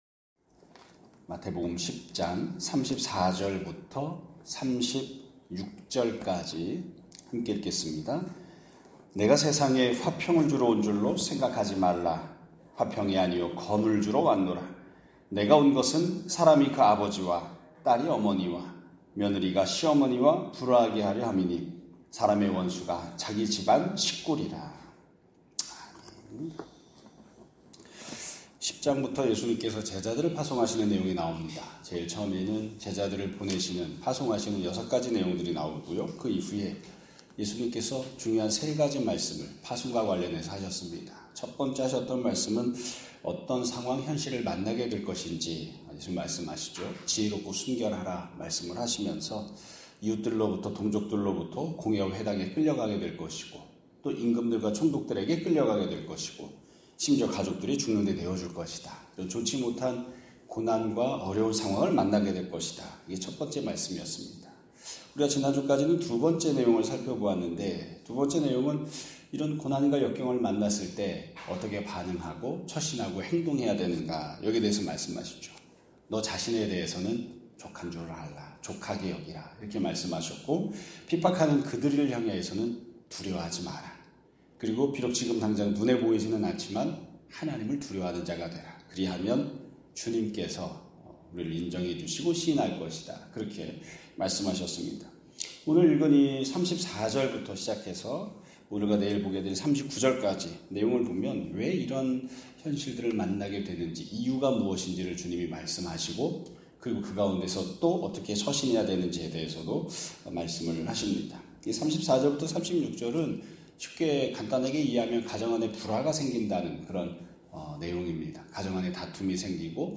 2025년 8월 18일 (월요일) <아침예배> 설교입니다.